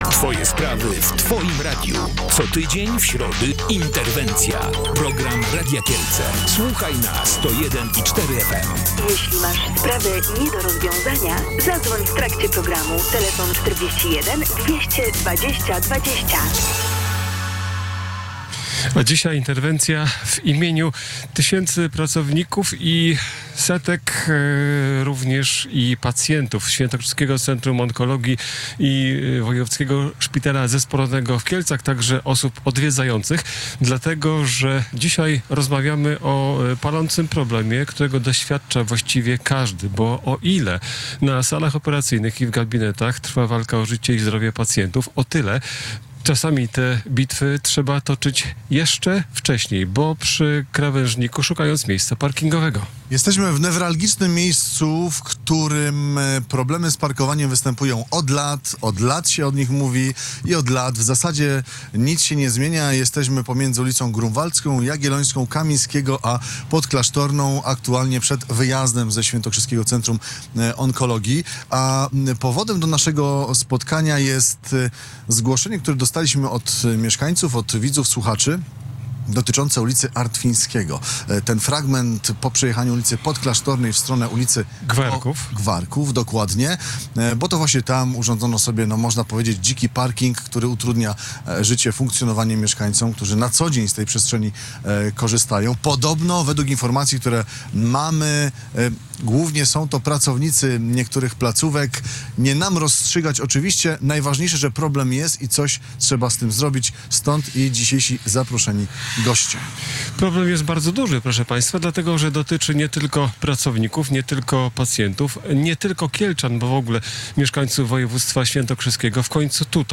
O tym jak rozwiązać problem miejsc parkingowych rozmawialiśmy w programie Interwencja.